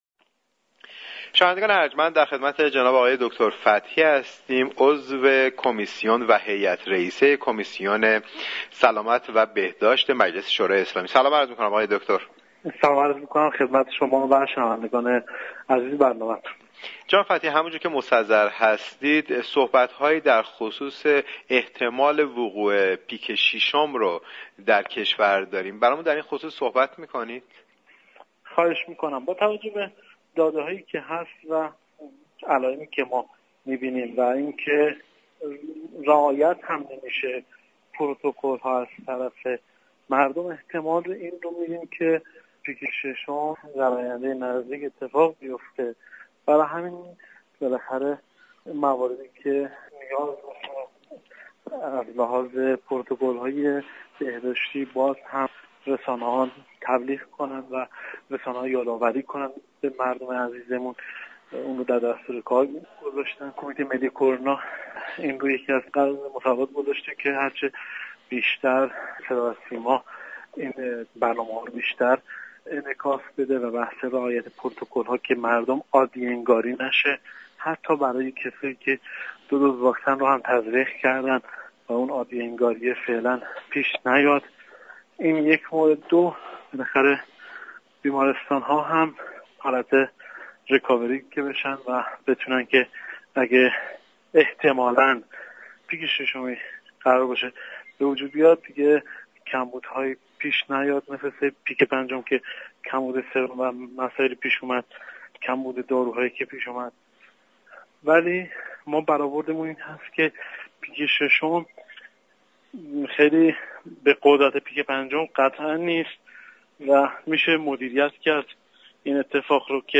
رویداد